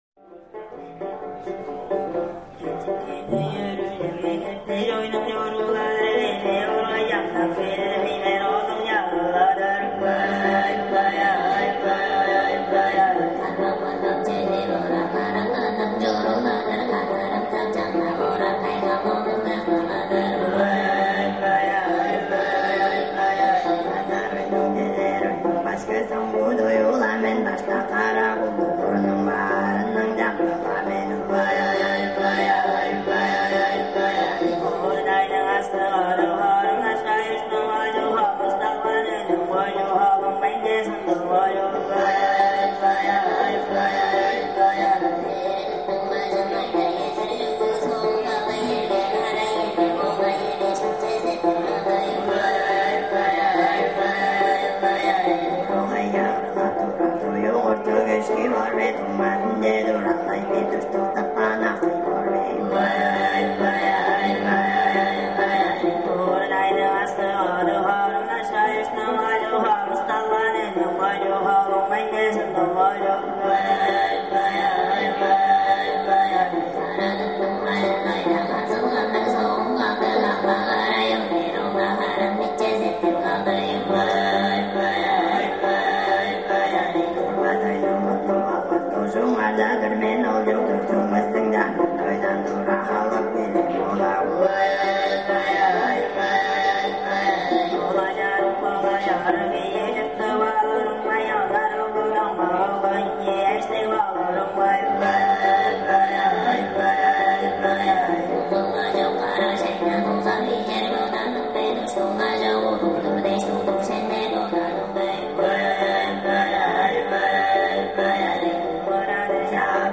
Instrument utilisé: vièle Igil.
Accompagnement: 1 Dochpuluur.
Nota: chanson extraite d'un concert live à Helsinki (Finlande)
tyva_kyzy_live_equi_ata.mp3